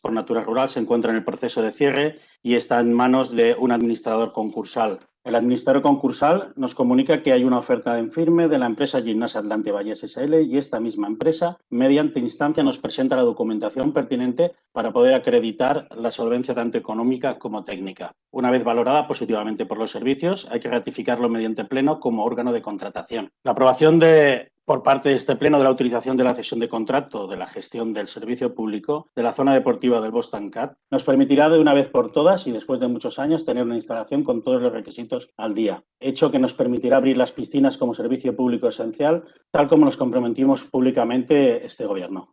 El regidor d’Esport,
Ple extraordinari Bosc Tancat